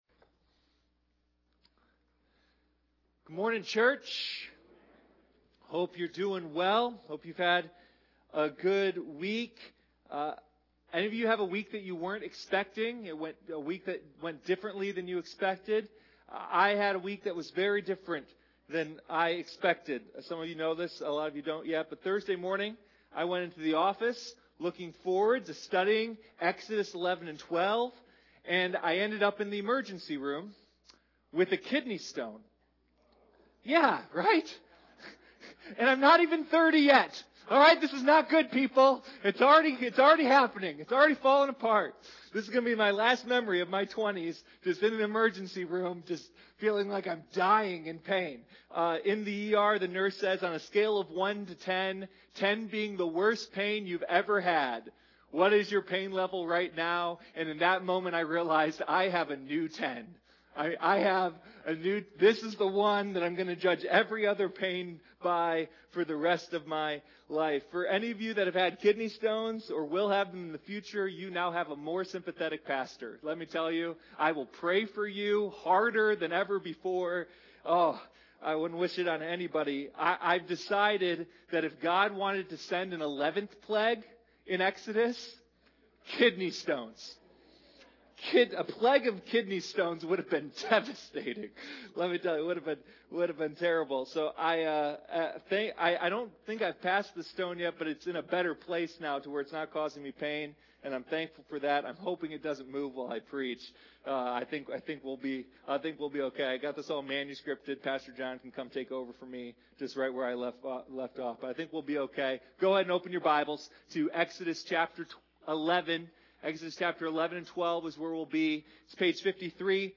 Sunday Morning Rescue: A Study of the Exodus